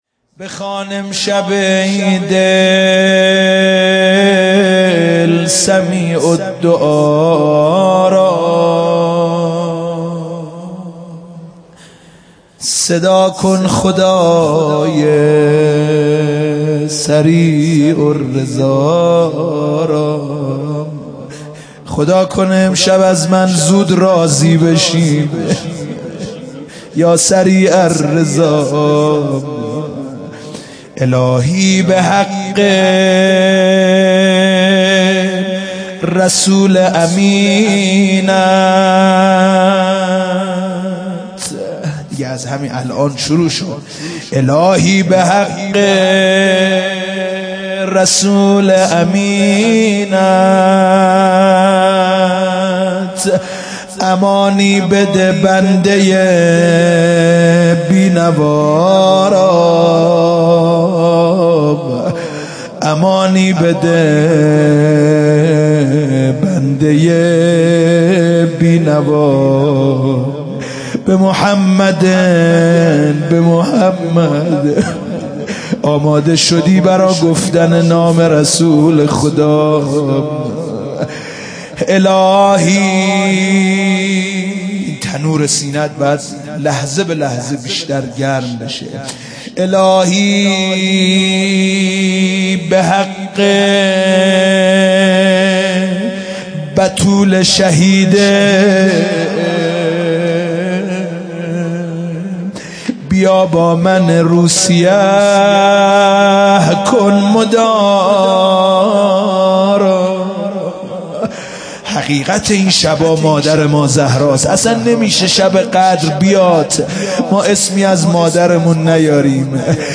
روضه شب قدر